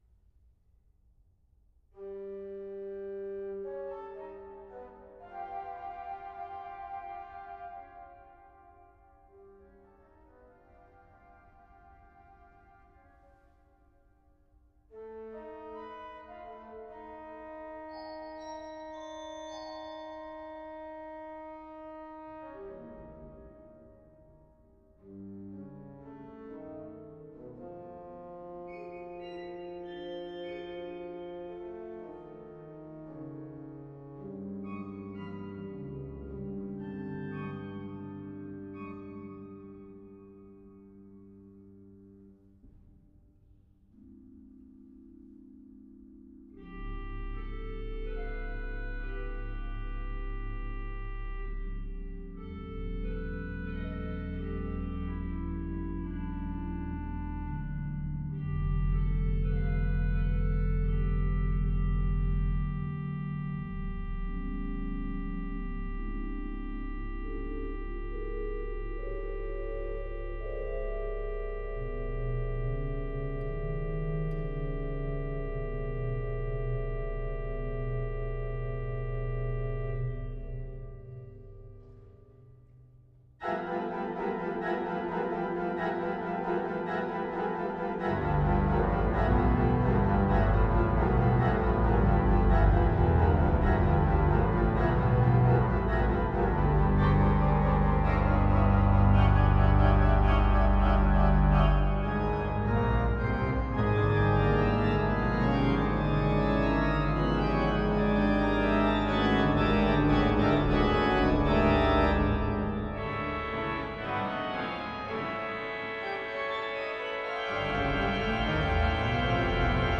Musik für Orgel